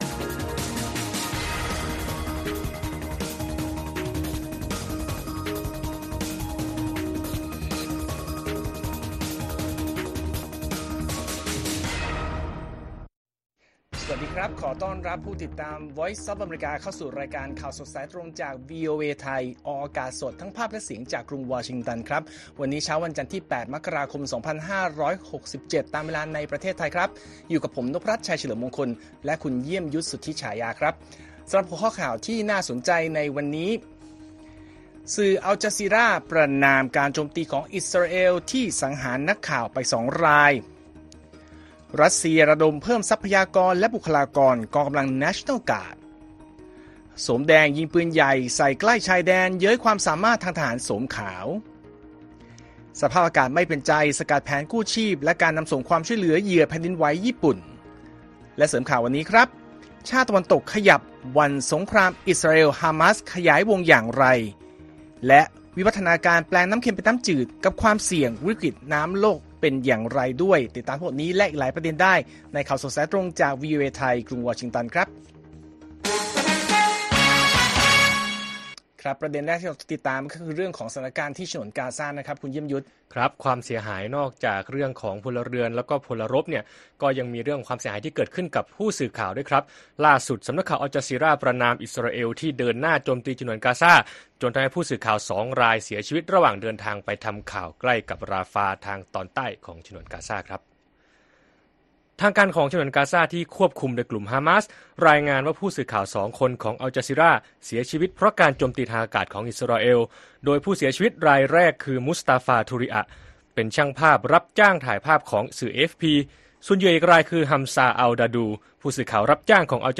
ข่าวสดสายตรงจากวีโอเอไทย วันจันทร์ ที่ 8 มกราคม 2567